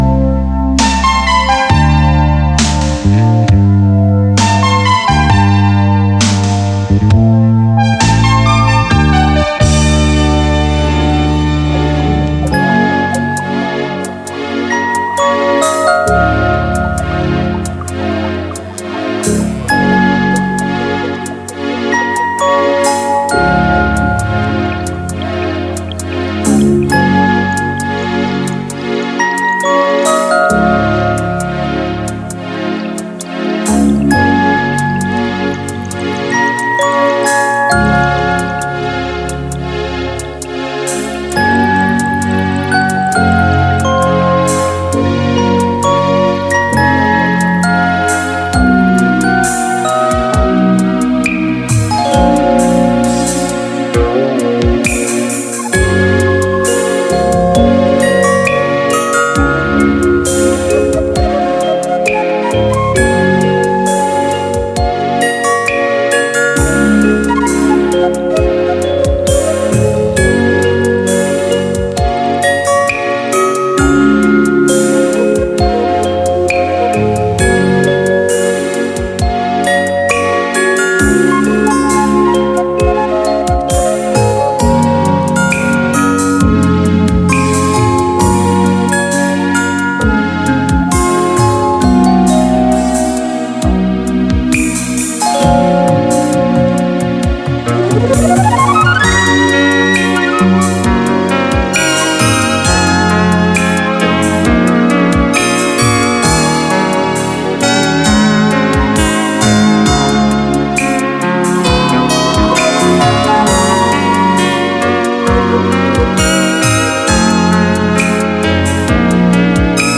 今回はJAZZやROCK、アニメ曲まで横断して選曲。一癖あるが日常に鳴らして馴染むMIX。